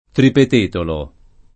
[ tripet % tolo ]